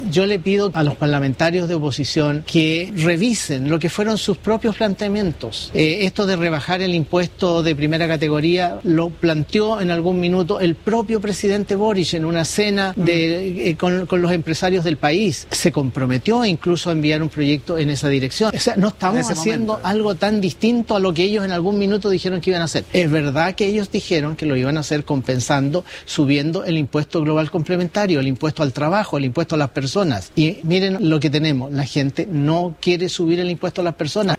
En entrevista con Mesa Central, el secretario de Estado respondió a las críticas de quienes sostienen que la iniciativa favorece a los sectores de mayores ingresos.